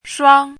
shuāng
拼音： shuāng
注音： ㄕㄨㄤ
shuang1.mp3